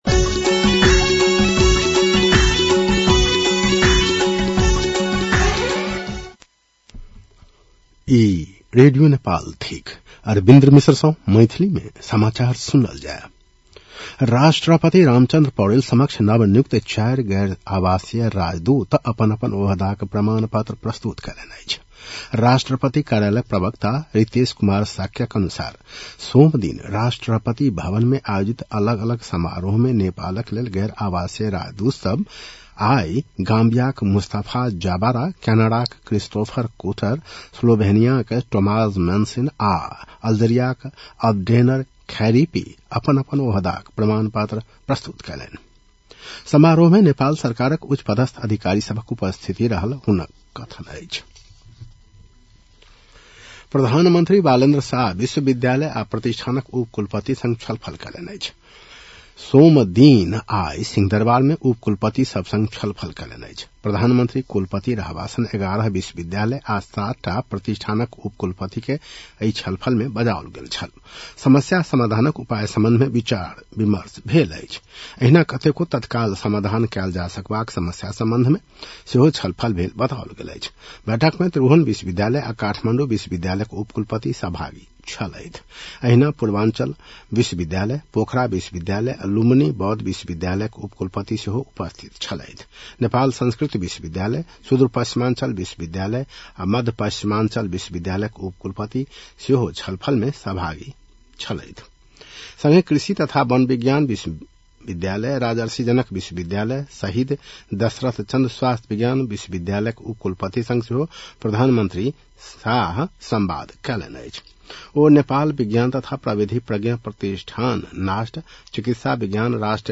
मैथिली भाषामा समाचार : ७ वैशाख , २०८३